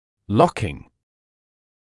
[‘lɔkɪŋ][‘локин]«заклинивание» (при открывании или закрывания рта)